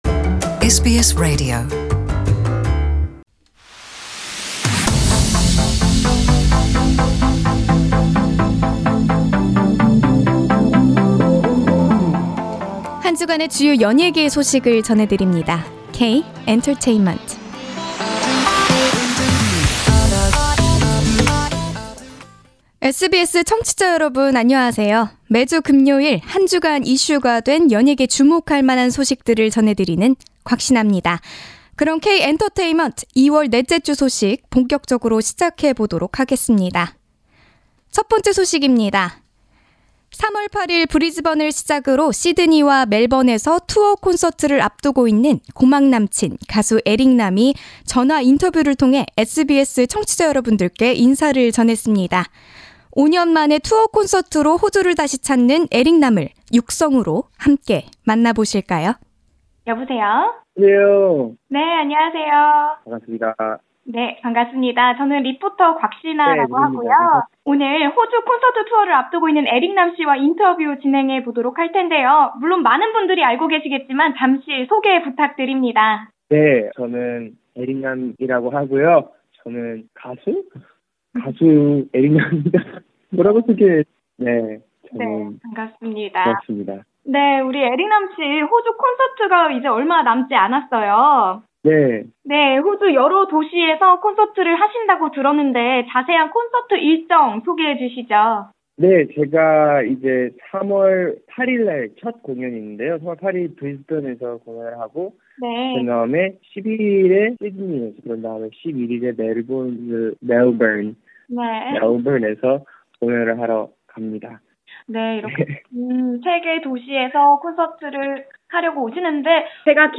One of South Korea’s hottest singer-songwriters and entertainers, ERIC NAM, has expressed his excitements about the upcoming shows in Sydney, Melbourne and Brisbane in an excluisve interview with SBS K-Entertainment, before the rendezvous with Australian fans in March.